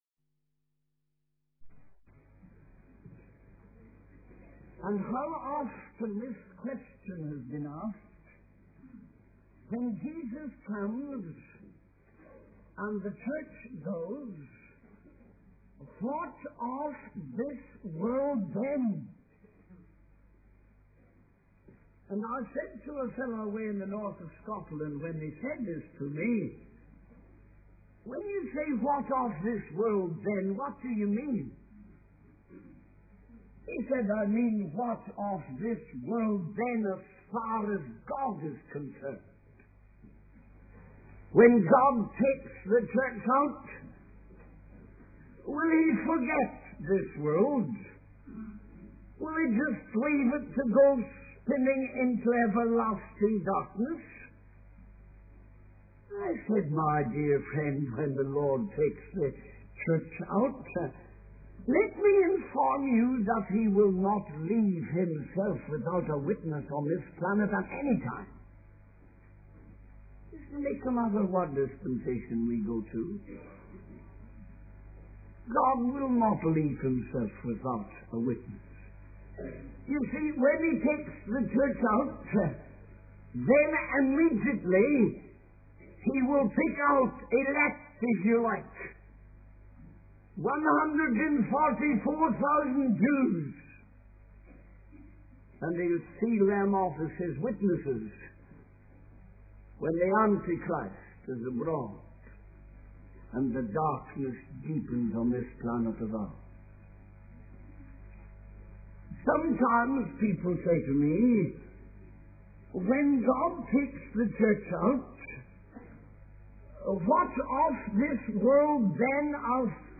In this sermon, the preacher discusses the worship of the dragon and the beast mentioned in verse 4.